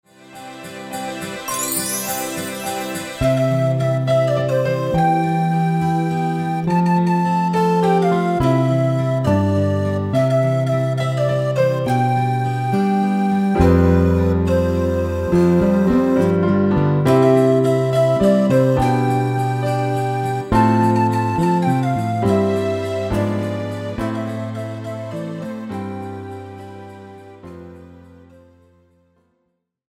Žánr: Pohádková
BPM: 104
Key: C
MP3 ukázka